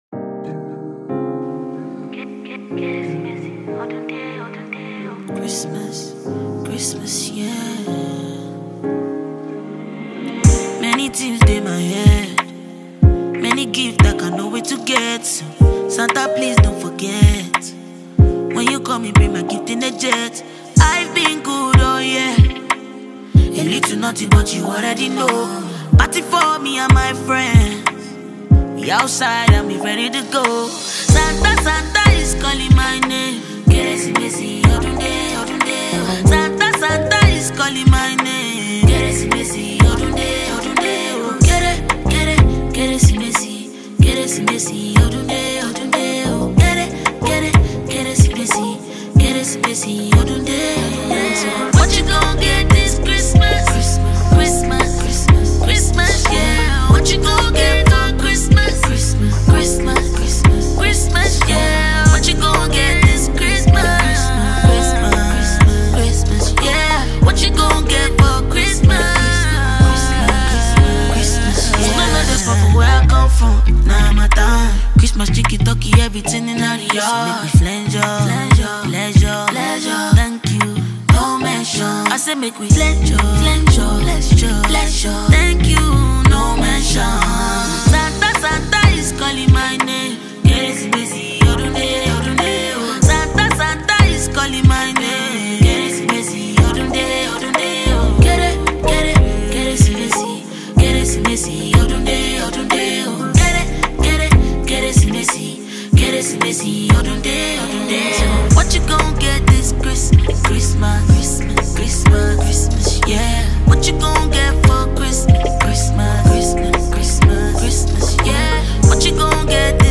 This groovy masterpiece